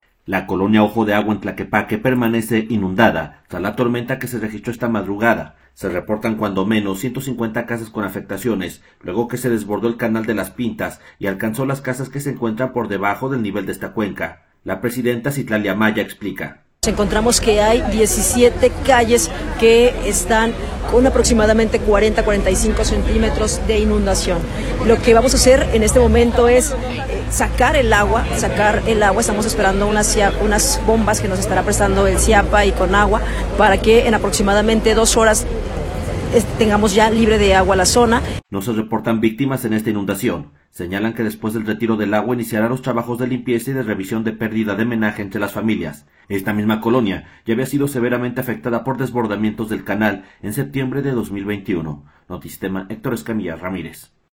audio La colonia Ojo de Agua en Tlaquepaque permanece inundada tras la tormenta que se registró esta madrugada. Se reportan cuando menos 150 casas con afectaciones luego que se desbordó el canal de Las Pintas y alcanzó las casas que se encuentran por debajo del nivel de esta cuenca. La presidenta Citlalli Amaya, explica.